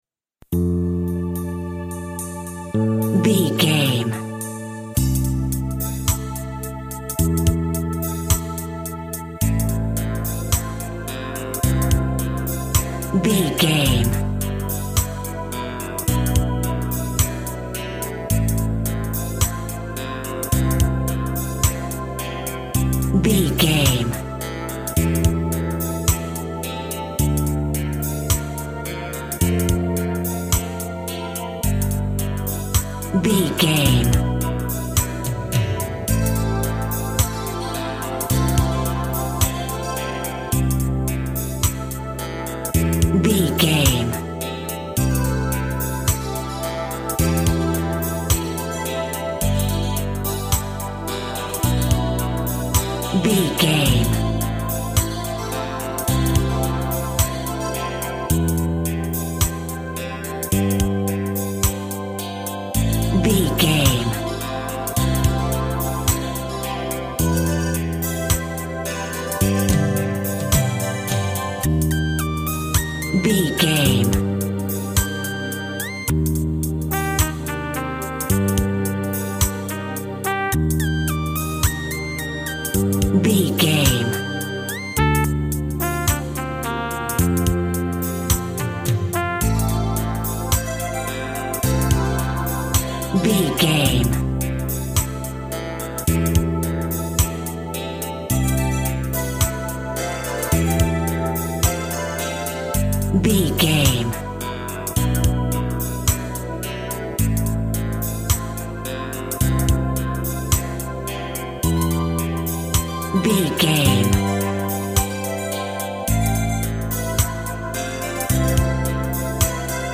Eighties World Music.
Aeolian/Minor
Slow
uplifting
futuristic
hypnotic
dreamy
tranquil
meditative
drums
bass guitar
synthesiser
trumpet
electronic
synth bass
synth lead